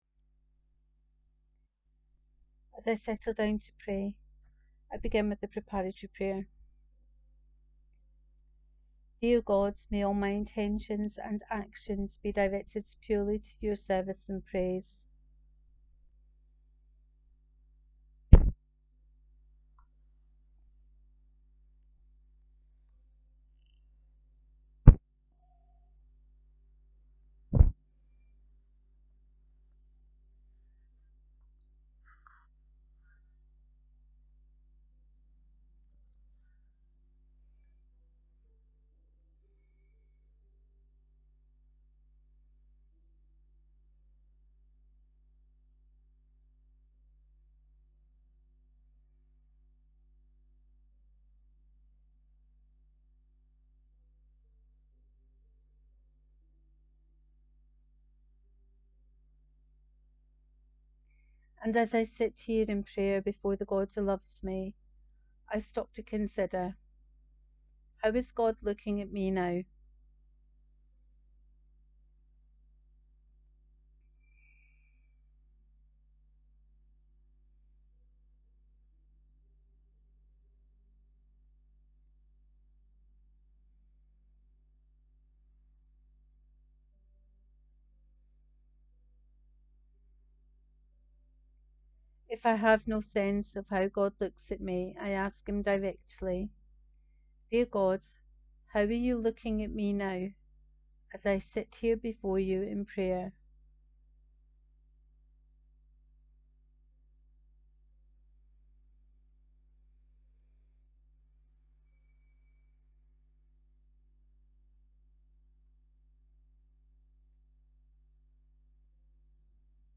Guided prayer.